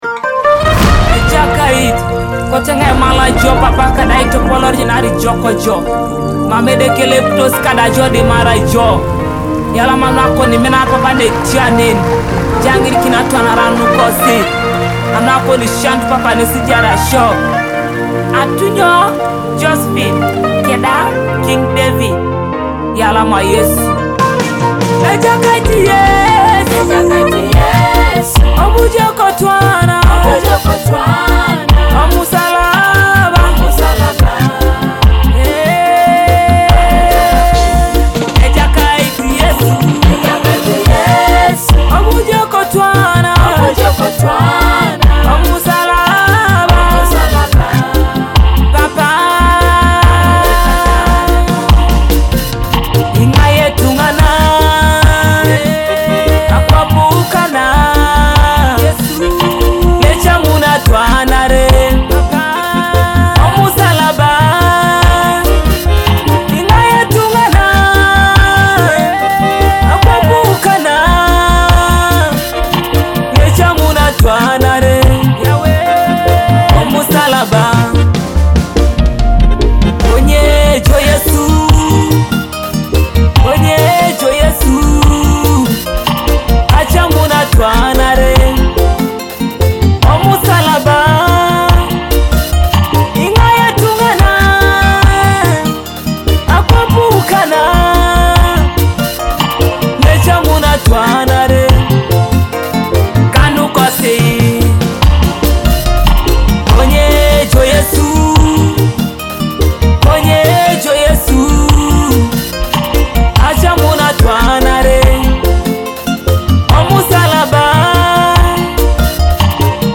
Teso gospel worship track